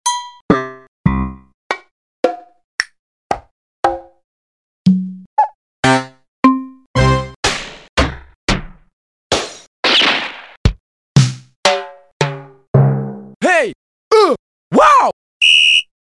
yamaha rx5 drum machine
info WAVETABLE internal ROM contains 24 PCM samples at 25 kHz- 8/12 bits, split in 2 ROM banks (at IC 45/46 -about 1 megabyte).
info CARTRIDGE The wavetable can be expanded using special custom WRC cartridge models also suitable for module PTX8.
rx5-cartridge.mp3